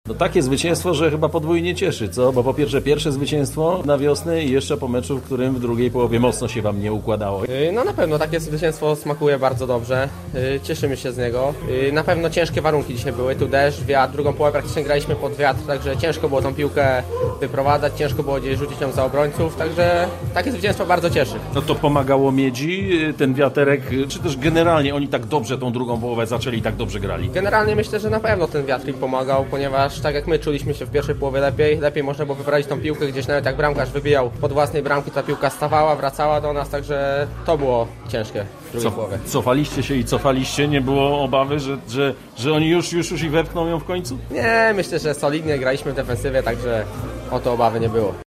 z uśmiechem na twarzy opowiadał o tym, co działo się na boisku: